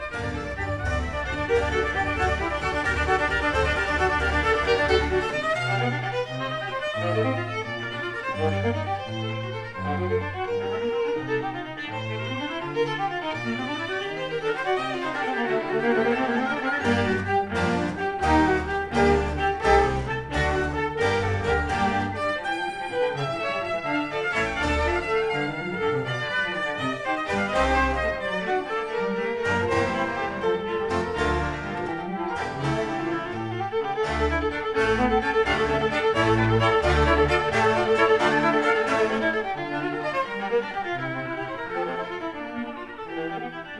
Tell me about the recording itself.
1960 stereo recording made by